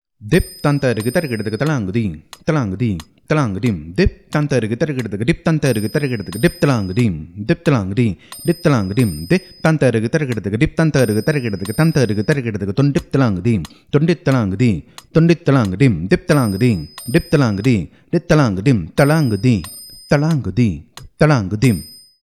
This is a mukthayam of 32 beats, which is a combination of both chaturashra nade and trishra nade.
Konnakol